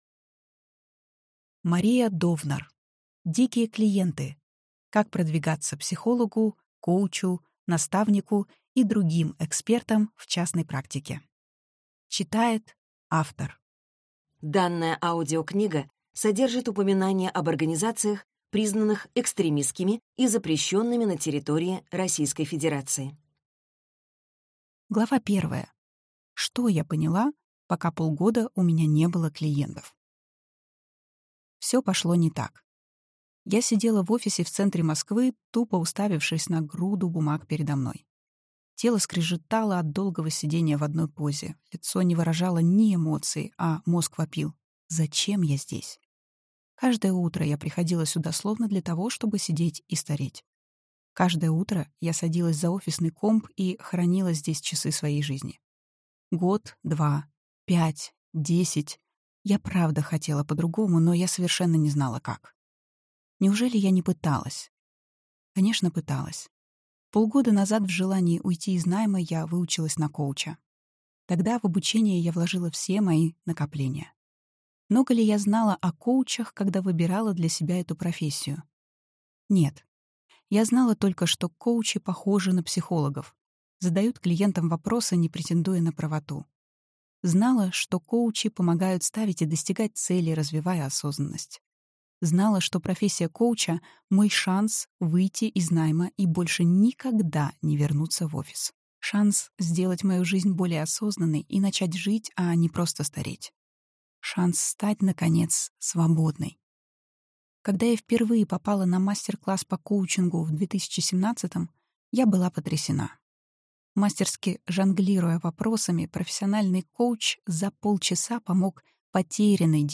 Аудиокнига Дикие клиенты. Как продвигаться психологу, коучу, наставнику и другим экспертам в частной практике | Библиотека аудиокниг